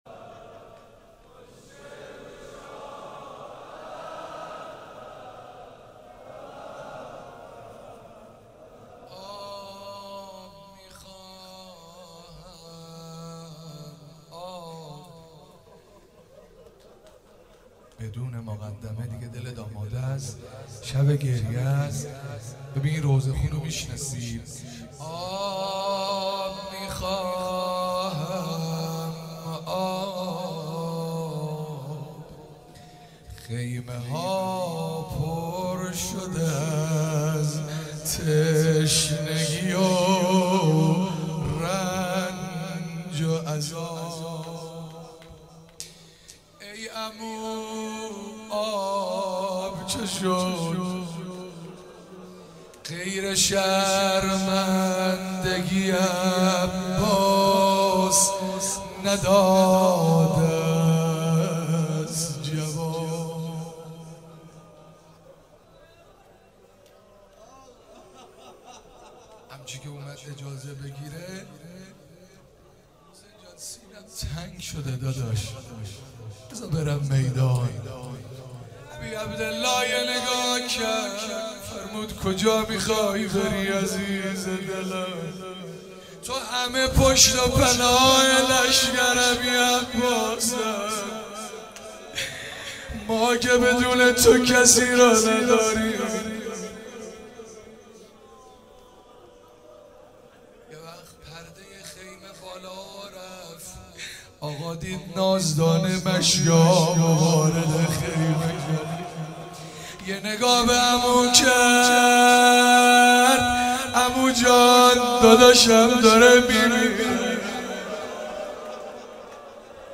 مراسم عزاداری ظهر تاسوعا 94